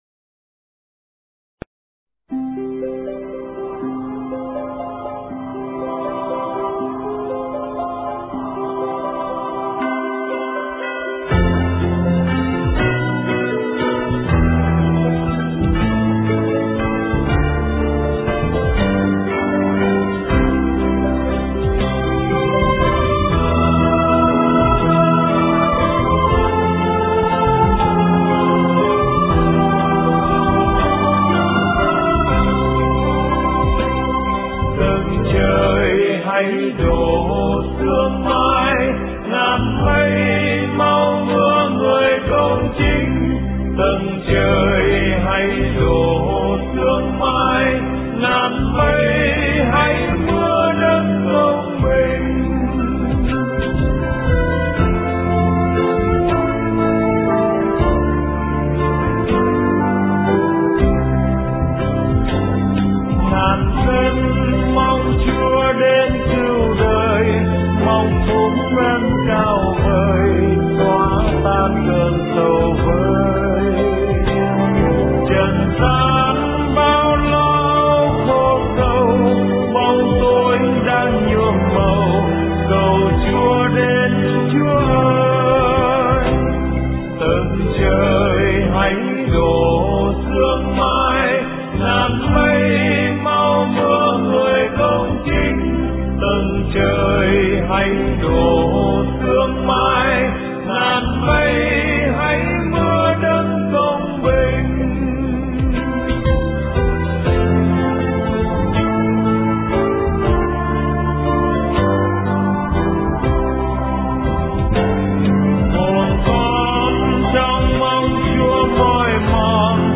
Dòng nhạc : Giáng Sinh